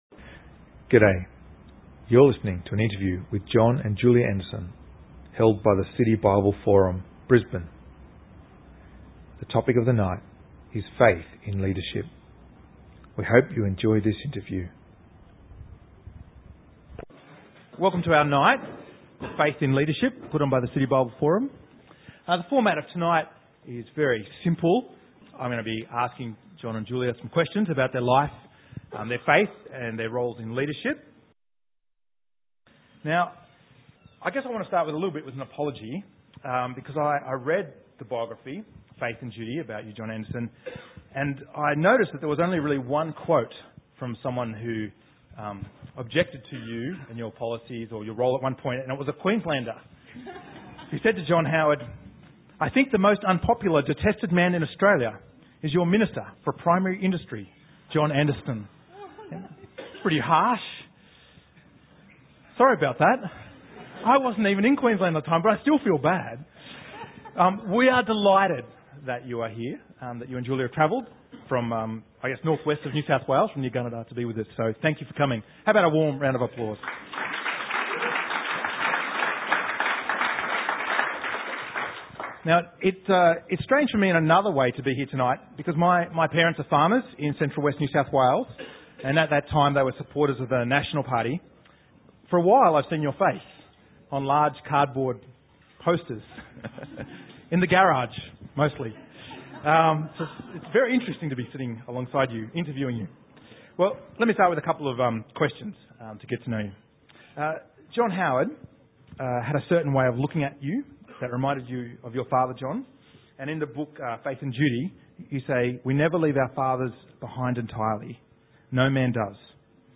Faith in Leadership - Interview